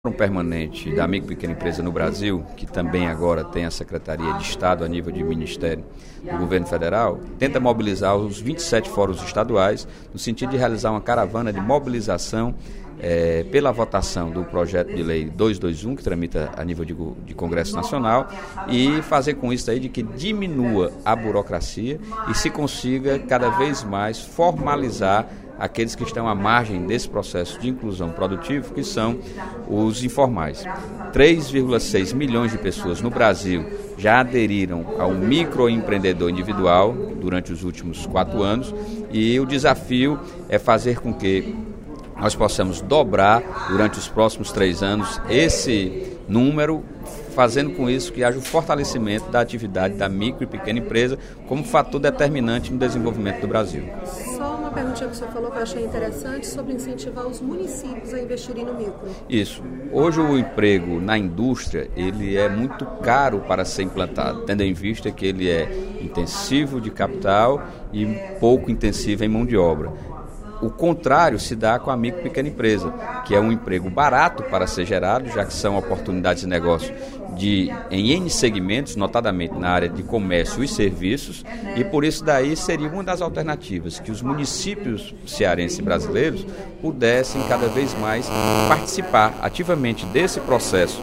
No primeiro expediente da sessão plenária desta sexta-feira (14/02), o deputado Sérgio Aguiar (Pros) destacou a importância das micro e pequenas empresas na economia do País e a necessidade de uma mobilização para ampliar a atuação dos micro e pequenos empreendedores.